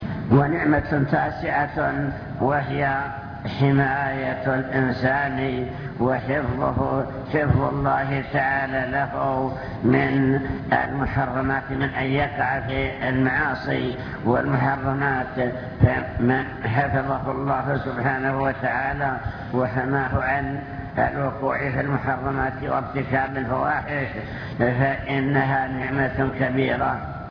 المكتبة الصوتية  تسجيلات - محاضرات ودروس  محاضرة بعنوان شكر النعم (2) نعم الله تعالى وعظمها